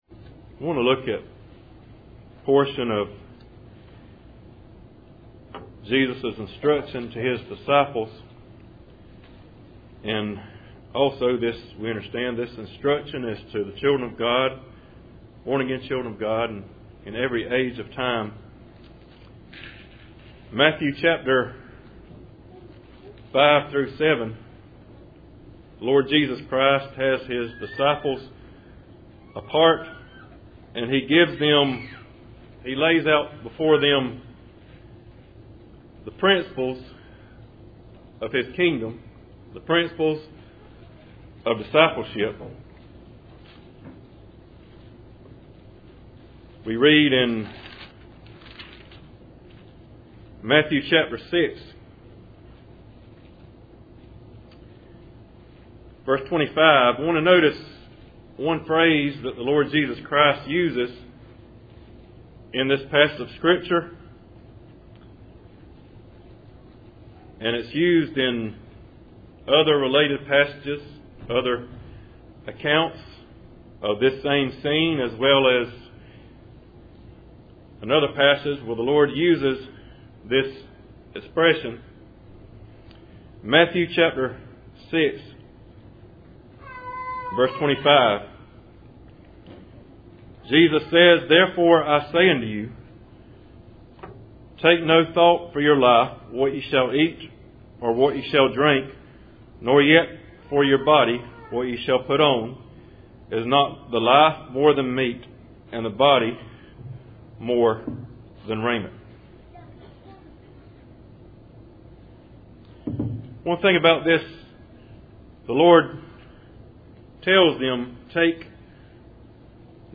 Service Type: Cool Springs PBC Sunday Morning